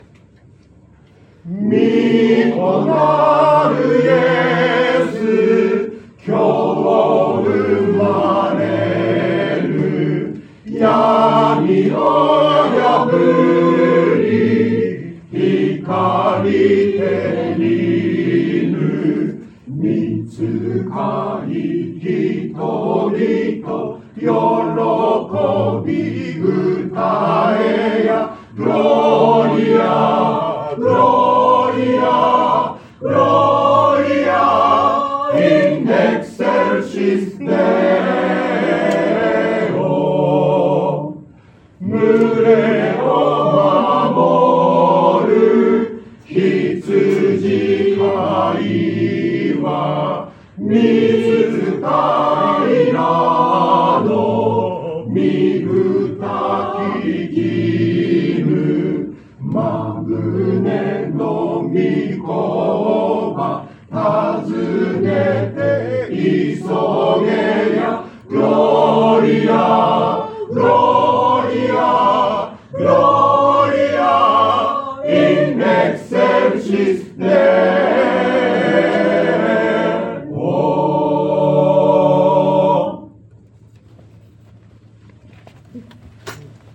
聖歌隊による賛美の歌声
礼拝での奉仕の様子